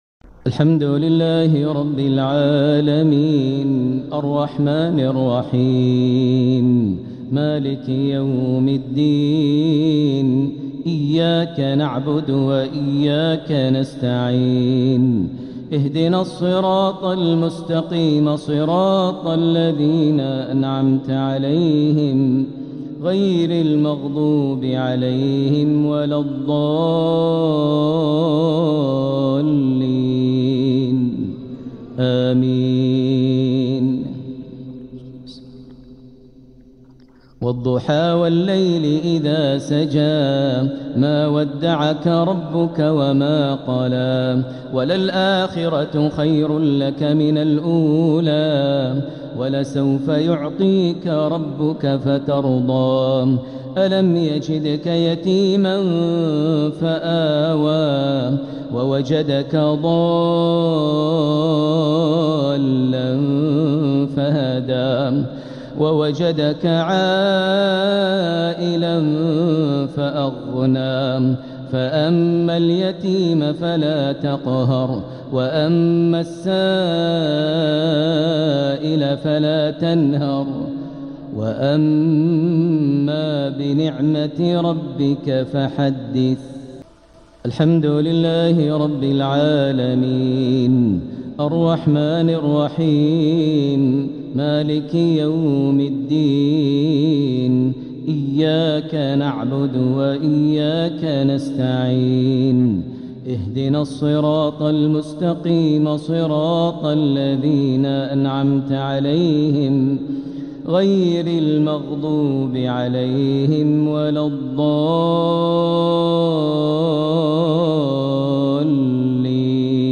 الإصدار القرآني المميز | لفروض شهر جمادى الأولى لعام 1447هـ | لفضيلة الشيخ د. ماهر المعيقلي > سلسلة الإصدارات القرآنية للشيخ ماهر المعيقلي > الإصدارات الشهرية لتلاوات الحرم المكي 🕋 ( مميز ) > المزيد - تلاوات الحرمين